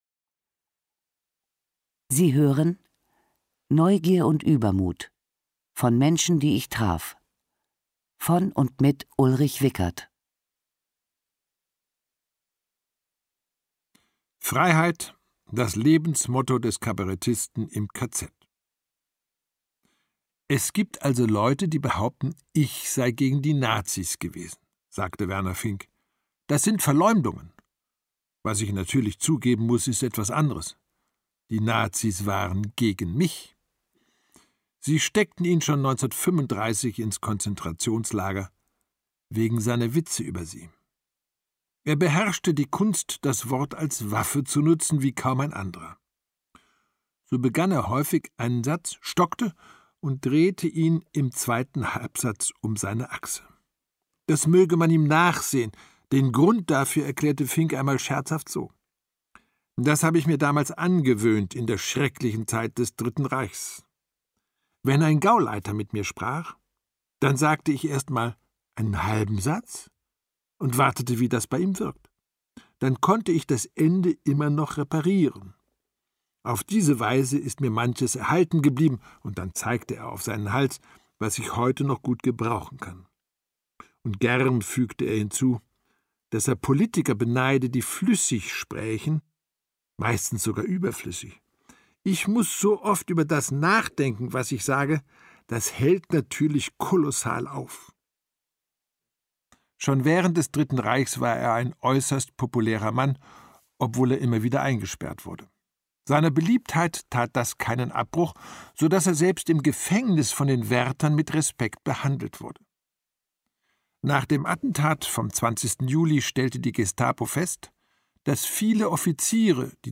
Ulrich Wickert (Sprecher)
Schlagworte BerühmtePersönlichkeit • Biografien/Erinnerungen • Biographie • CD • Diplomatensohn • Fünfziger Jahre • Geschichten • Hörbuch; Autorenlesung • Journalist • Menschen • Neugierde • Reisebeschreibung • Übermut • Wickert, Ulrich